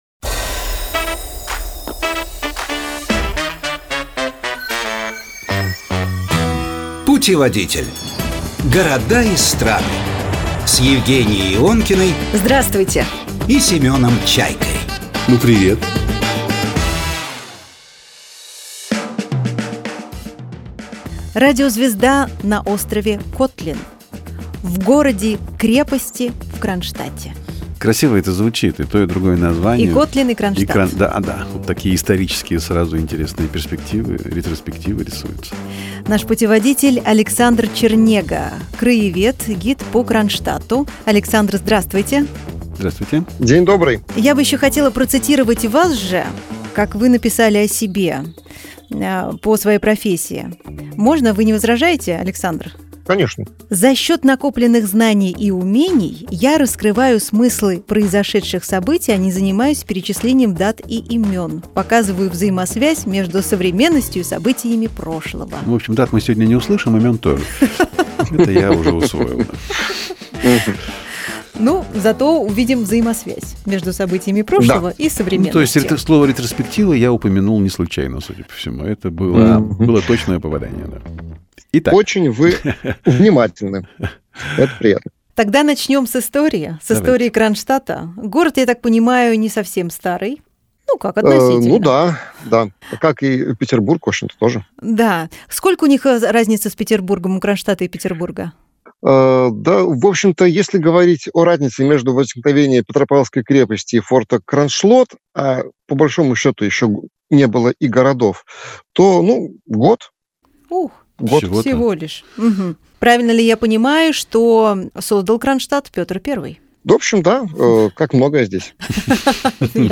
В студии программы «Путеводитель» гид по Кронштадту